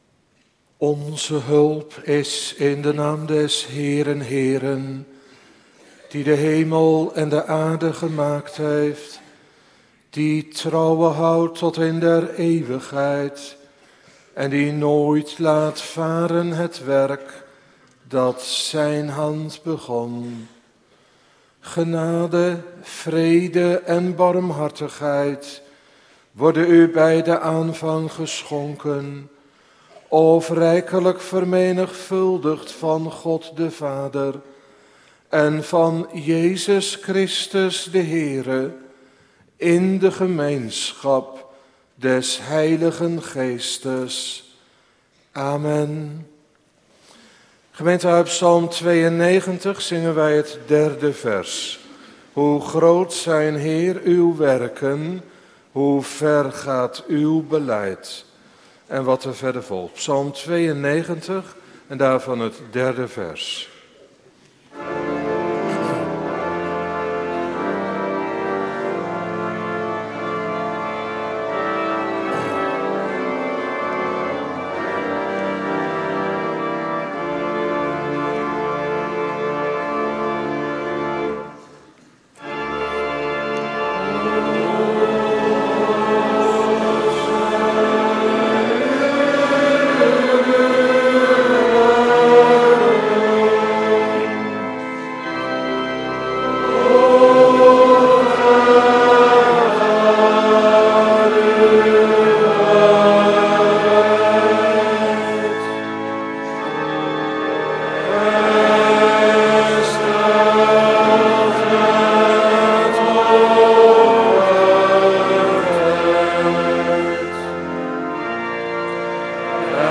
Tweede Paasdag Belijdenisdienst